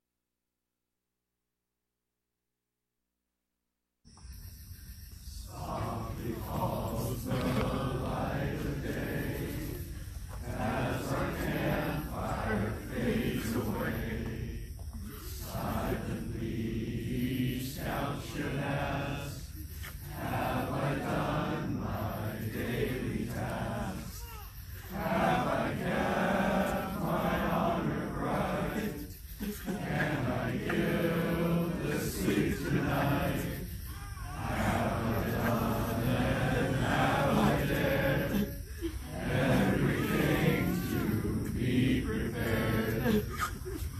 –Camp Counselors singing “Scout Vespers” during final campfire at Seph Mack Summer Residence Camp.